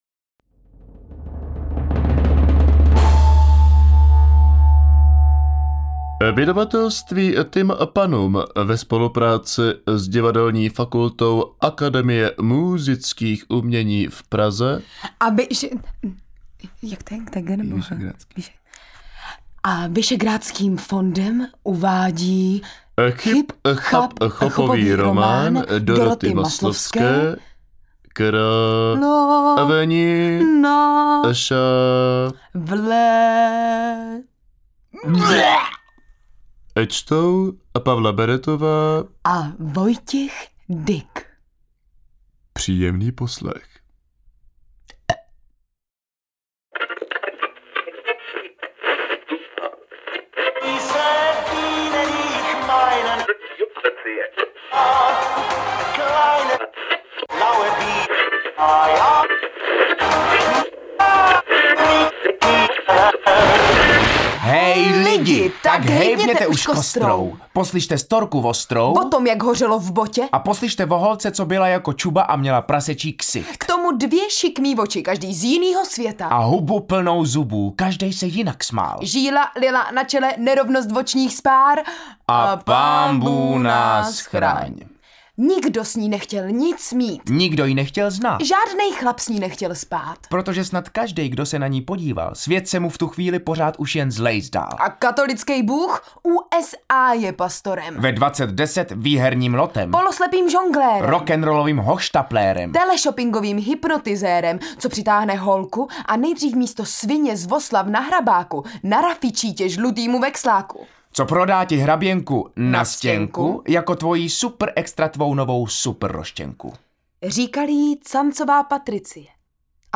Interpreti:  Pavla Beretová, Vojtěch Dyk
AudioKniha ke stažení, 13 x mp3, délka 2 hod. 58 min., velikost 416,0 MB, česky